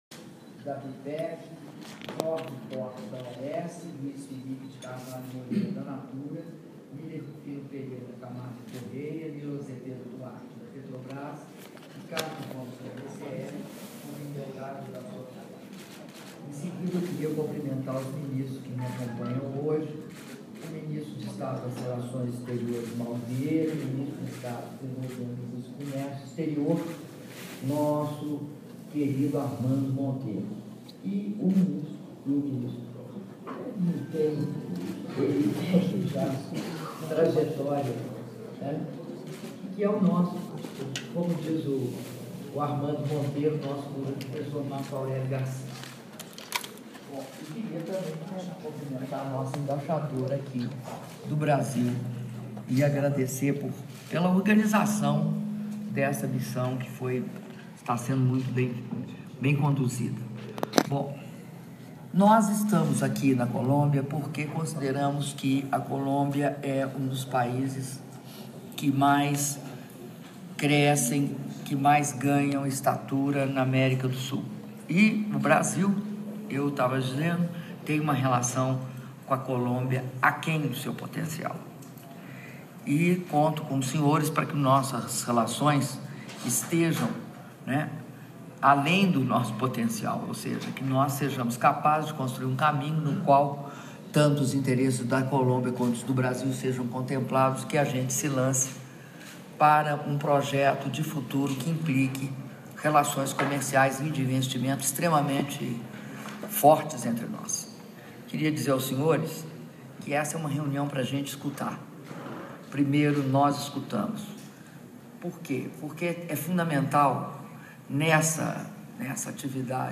Áudio do discurso da presidenta da República, Dilma Rousseff, durante encontro com lideranças empresariais - Bogotá/Colômbia (3min45s)